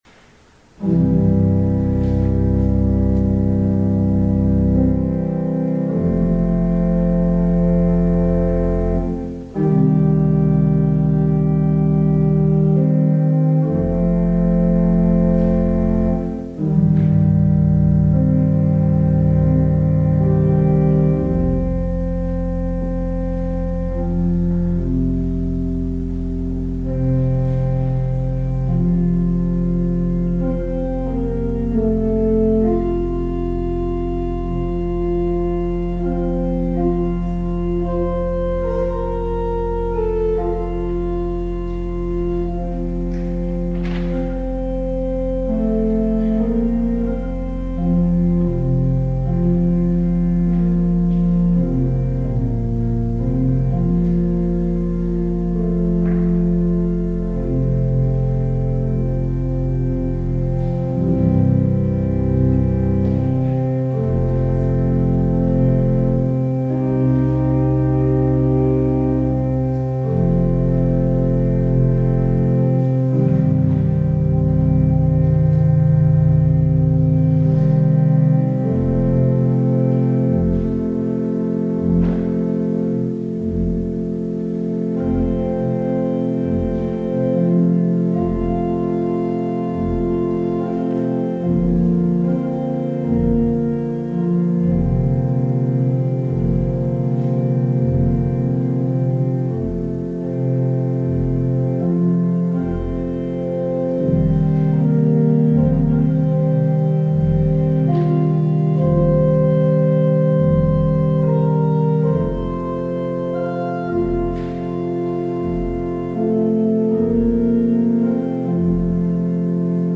Audio recording of the 10am service